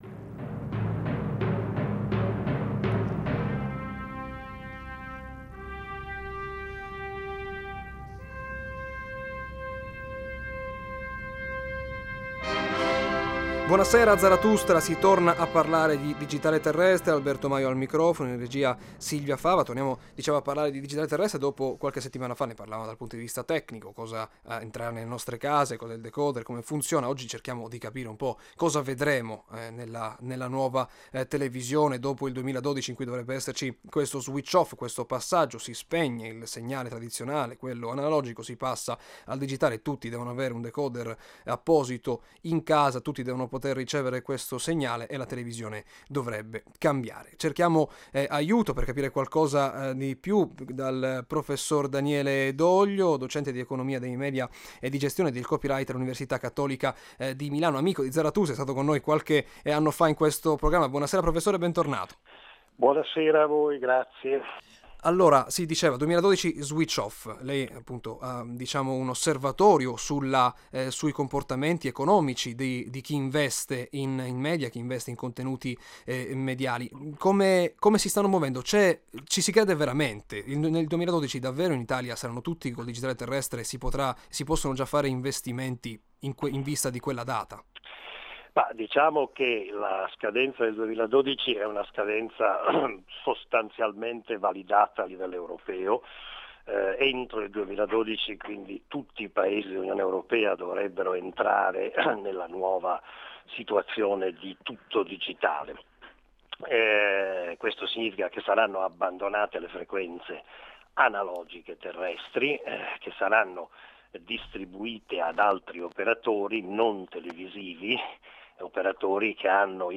Ascolta la puntata di Zarathustra andata in onda sabato 21 marzo, alle 18,05, su Radio Italia anni '60 - Emilia Romagna.